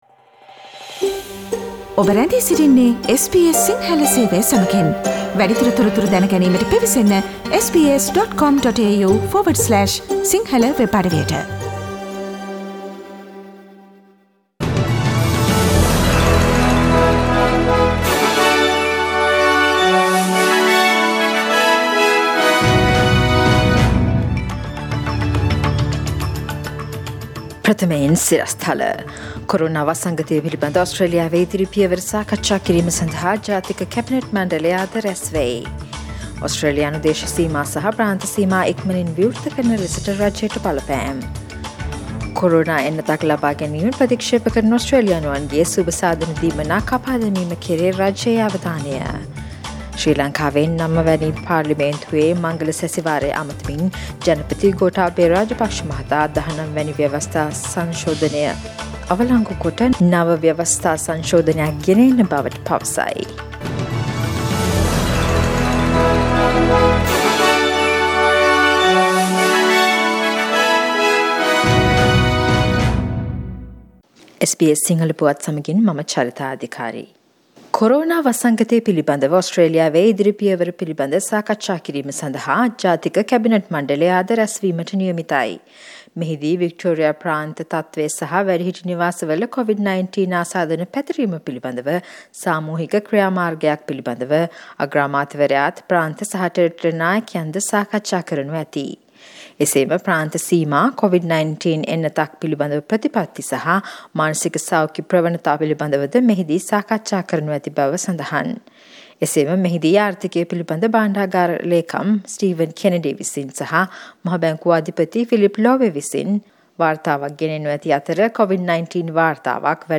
Daily News bulletin of SBS Sinhala Service: Friday 21 August 2020